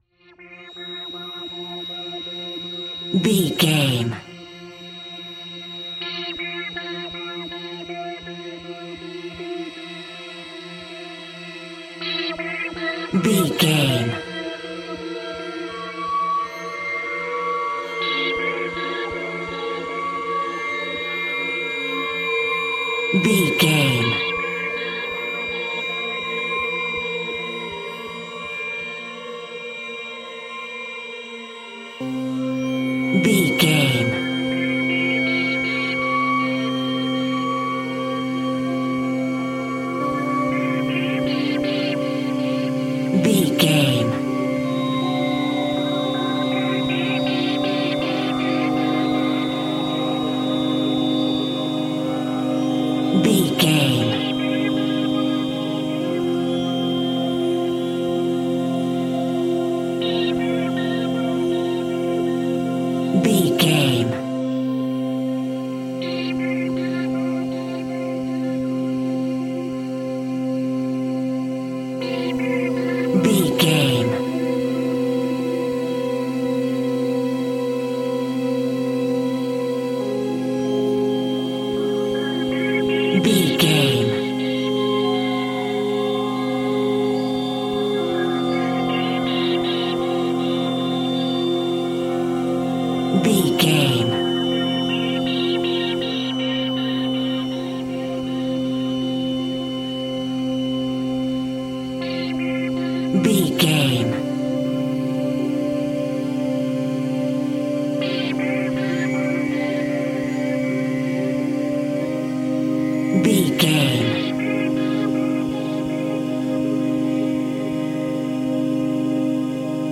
Diminished
Slow
scary
ominous
dark
suspense
eerie
synthesiser
horror
ambience
pads
eletronic